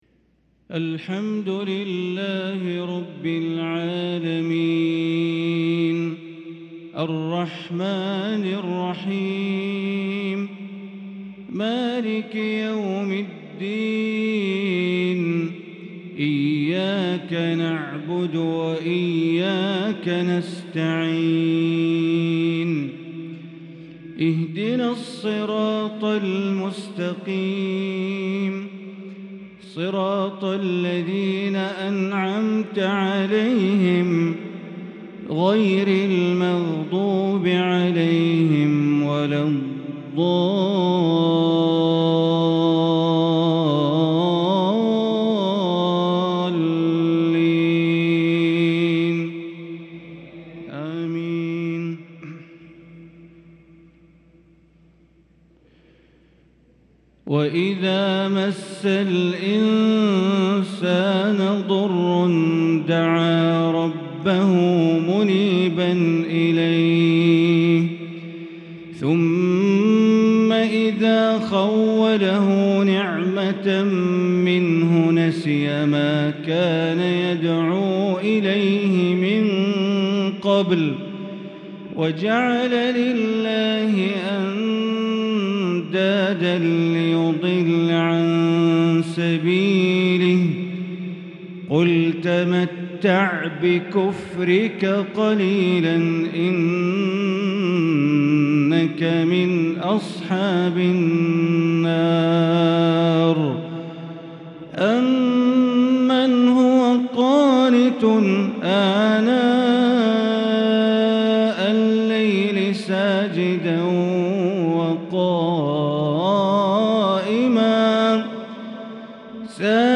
فجرالسبت ٦شوال ١٤٤٣هـ من سورة الزمر | Fajr prayer from surah az-Zumar 7-5-2022 > 1443 🕋 > الفروض - تلاوات الحرمين